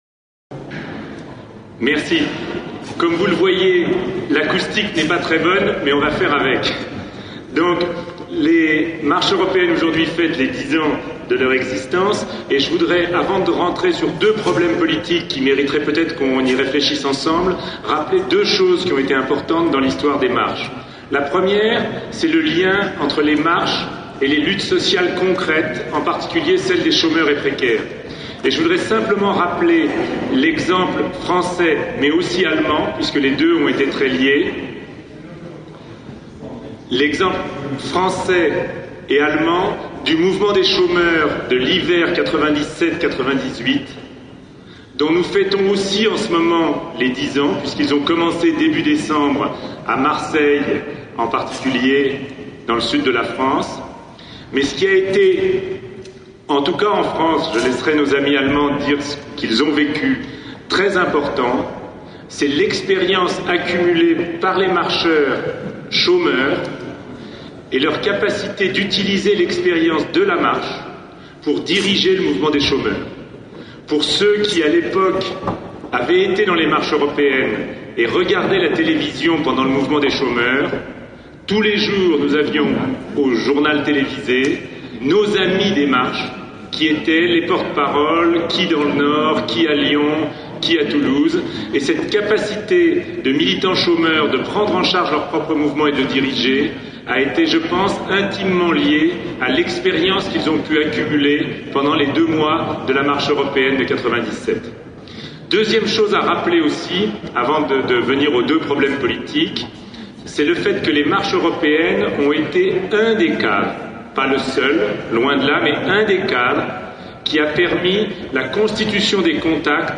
Conférence que les Marches européennes et l’ENU ont organisé à la Bourse du travail à Paris au mois de décembre 2007.